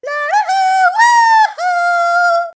One of Honey Queen's voice clips in Mario Kart 7